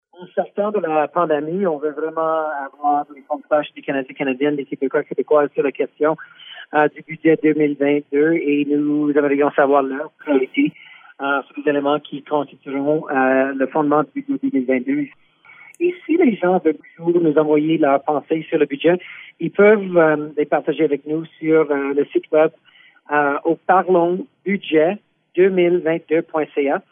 C’est Randy Boissonnault, ministre du Tourisme et ministre associé des Finances, qui a présidé la rencontre de mardi dernier. Il explique en quoi elle consistait :